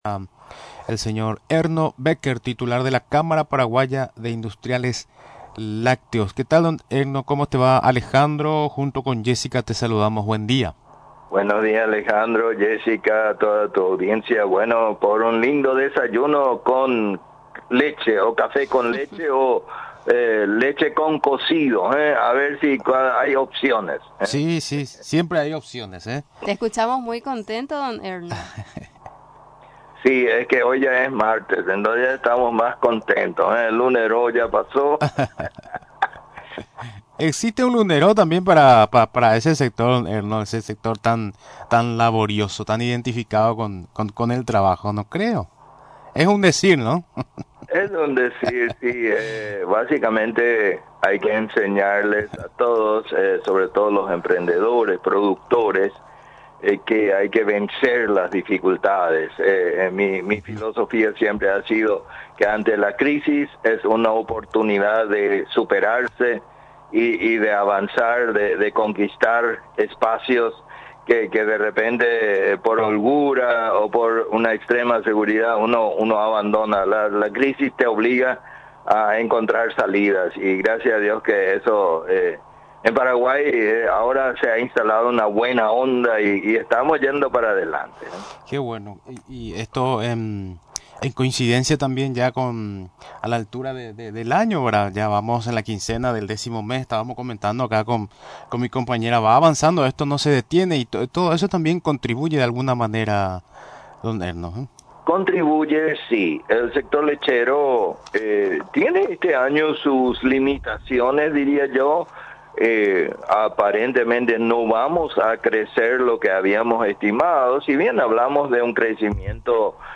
En comunicación con Radio Nacional del Paraguay, recordó que tienen una merma de un cuatro a cinco por ciento en lo que va del año.